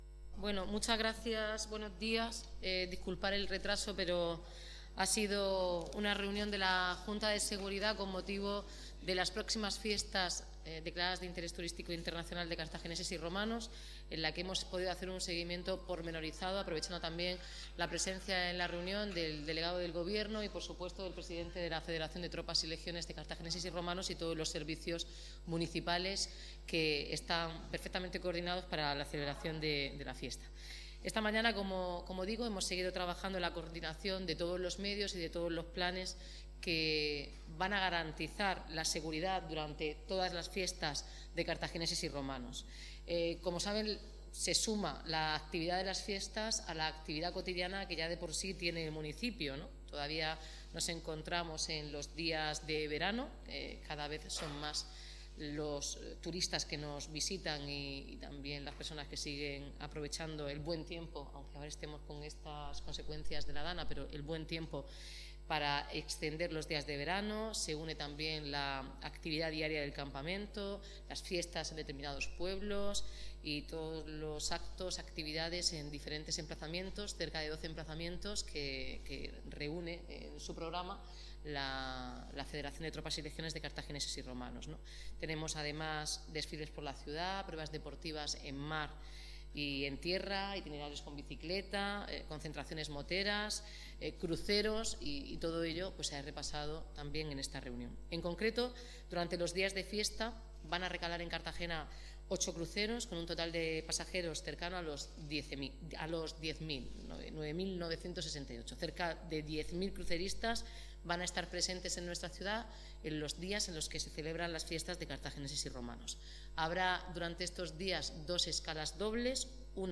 Declaraciones de Noelia Arroyo